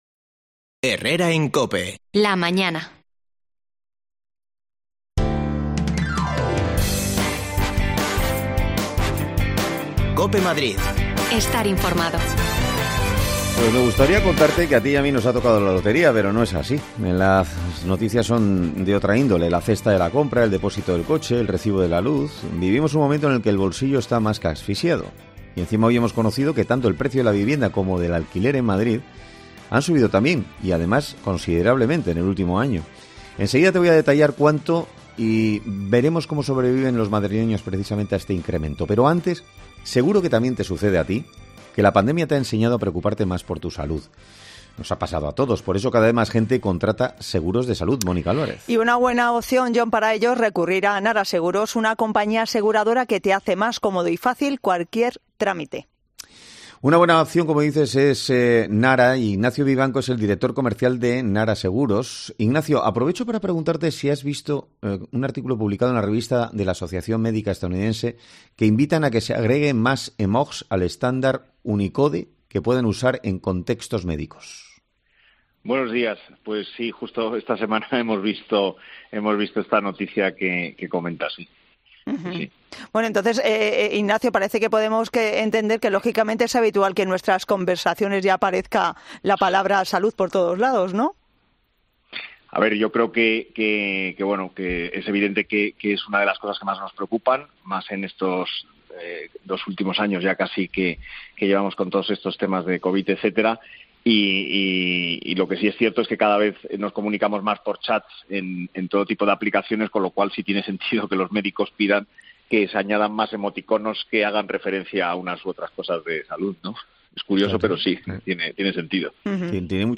AUDIO: El precio del alquiler está por las nubes en Madrid. Hablamos con inquilinos que tiene que hacer juegos malabares para llegar a fin de mes